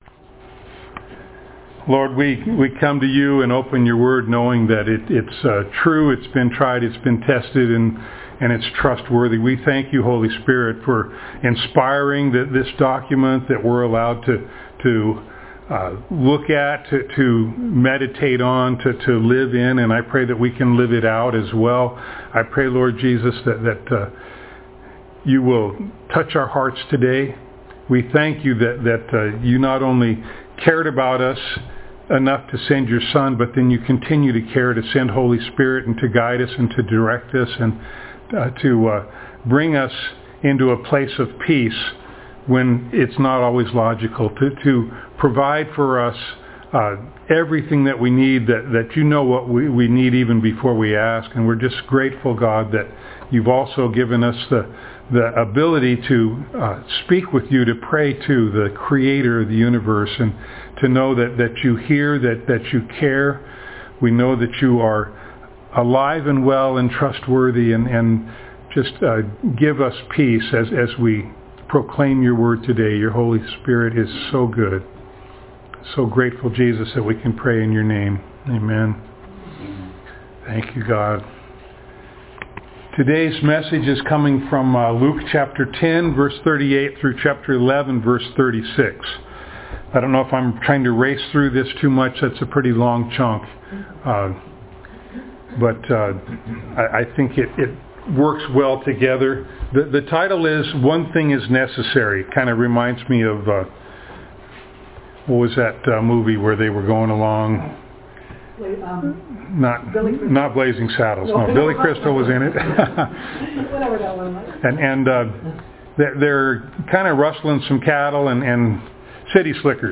Luke Passage: Luke 10:38-11:36 Service Type: Sunday Morning Download Files Notes « Who is My Neighbor?